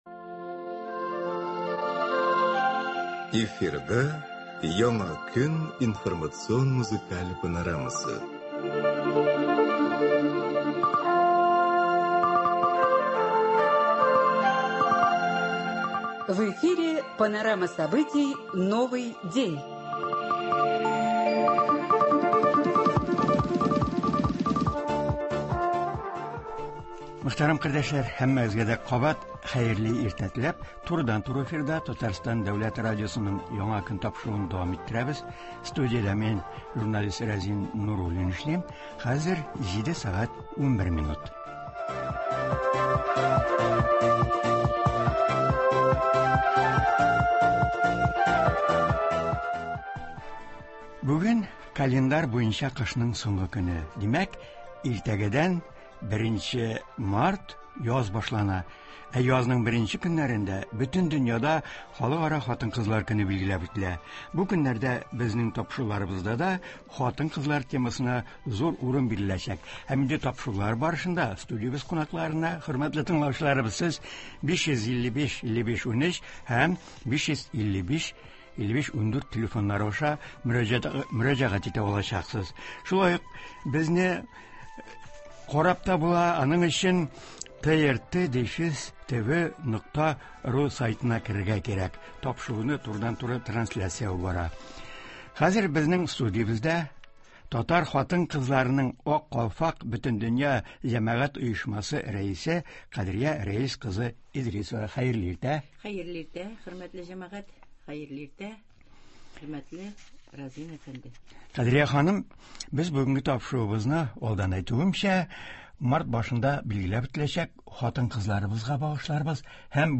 тыңлаучылар сорауларына җавап бирәчәк.